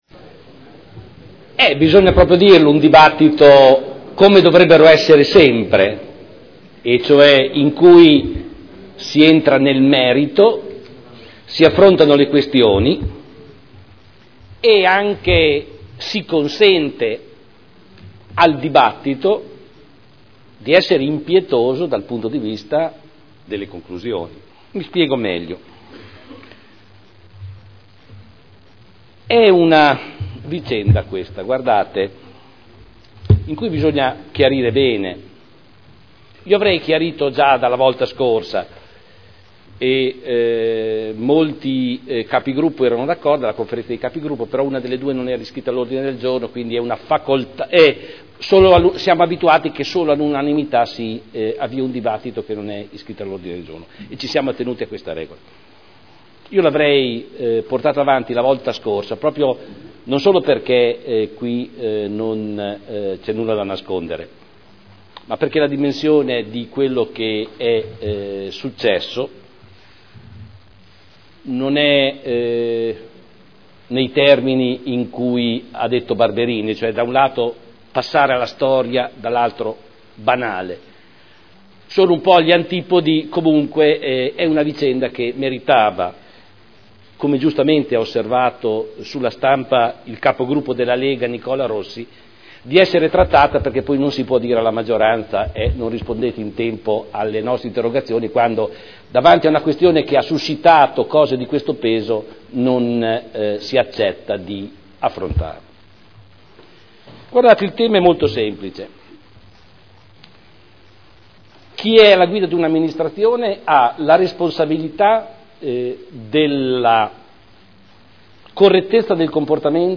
Giorgio Pighi — Sito Audio Consiglio Comunale
Seduta del 14/03/2011.